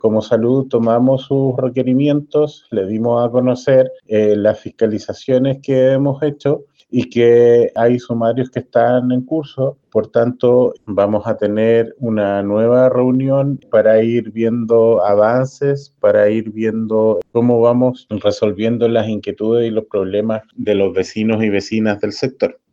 El jefe provincial de la Autoridad Sanitaria de la seremi de Salud, Claudio Millaguin, indicó que habrá un nuevo encuentro, con propuestas para responder a la demanda de los vecinos de Corral del Sur.